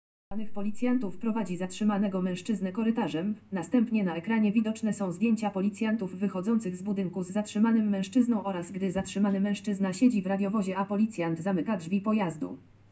Audiadeskrypcja filmu Policjanci prowadzą zatrzymanego mężczyznę - plik mp3